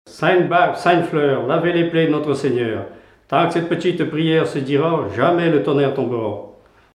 Genre brève
Veillées de chanteurs traditionnels
Pièce musicale inédite